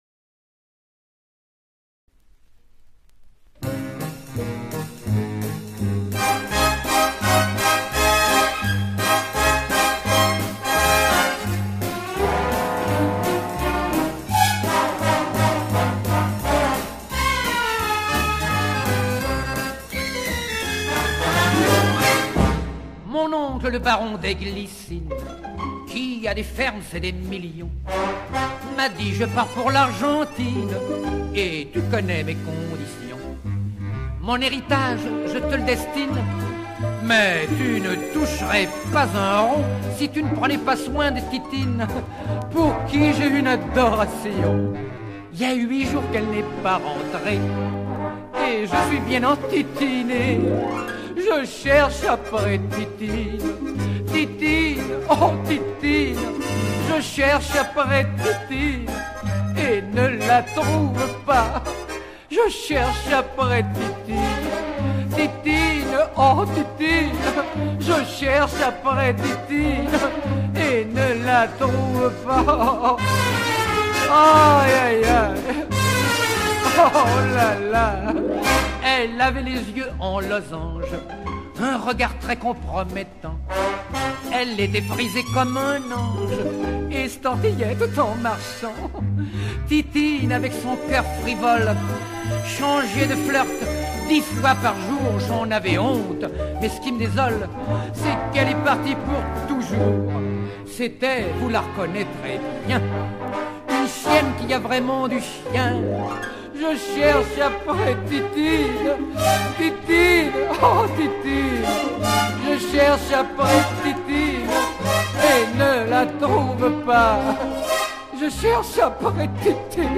chanson humoristique